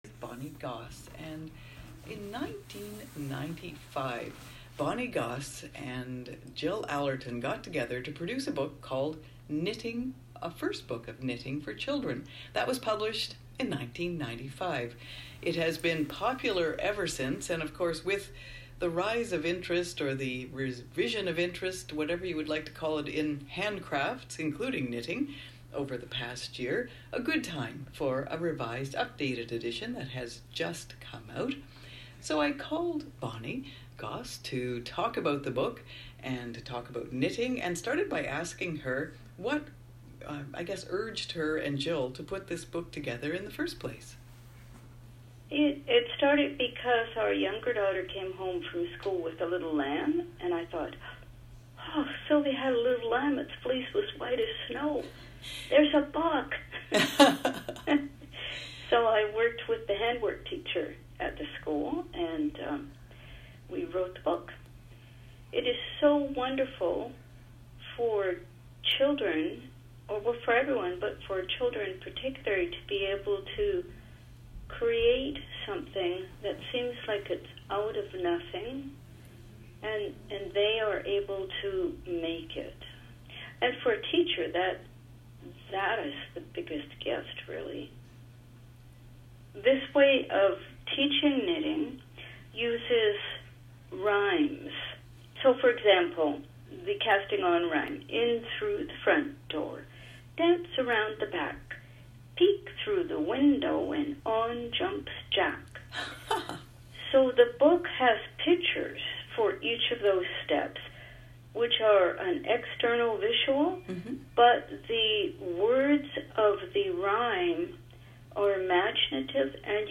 CBC_recording_knitting_interview.m4a